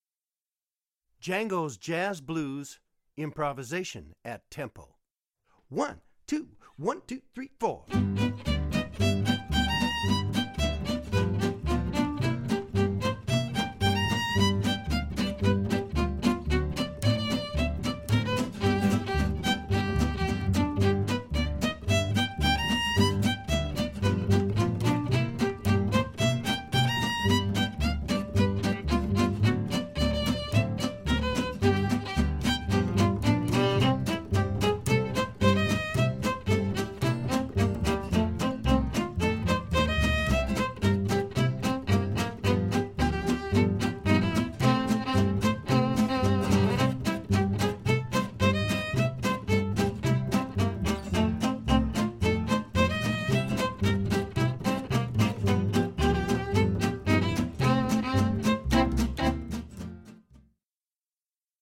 improv at tempo 1:01 8d.